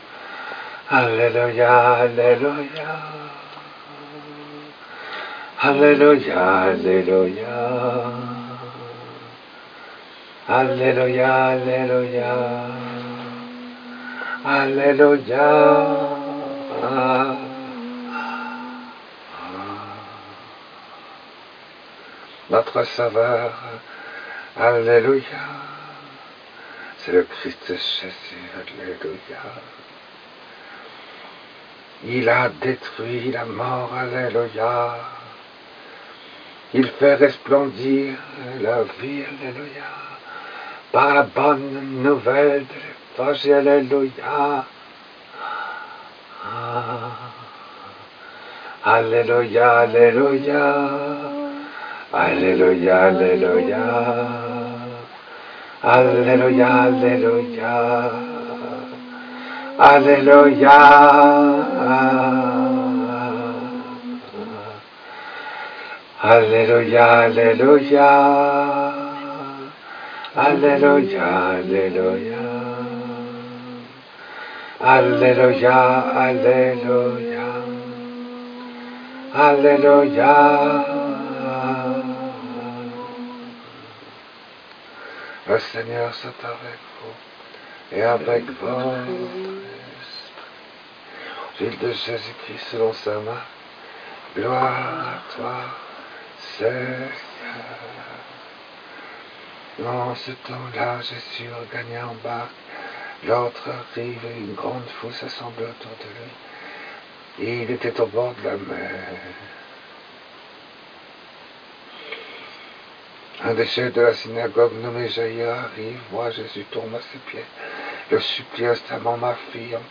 Texte de l’Homélie du Très Précieux Sang